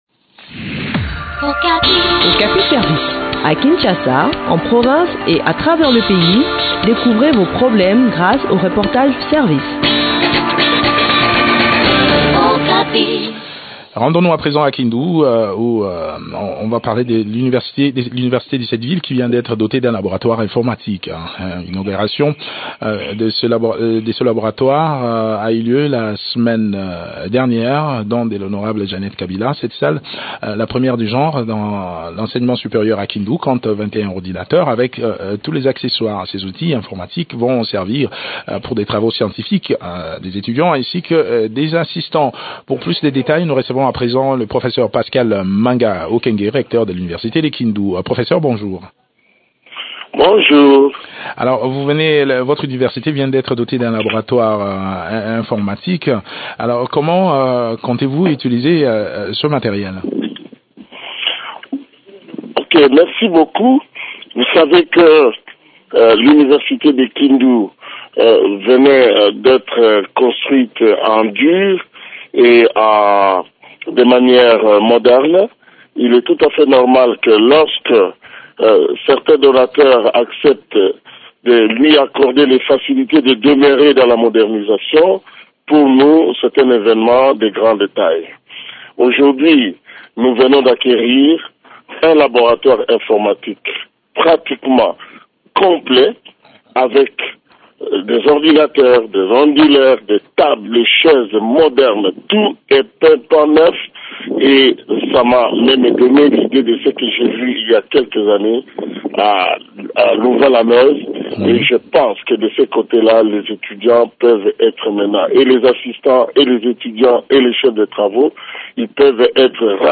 Le point sur l’acquisition de ces outils informatiques dans cet entretien